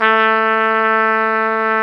Index of /90_sSampleCDs/Roland L-CDX-03 Disk 2/BRS_Tpt mf menu/BRS_Tp mf menu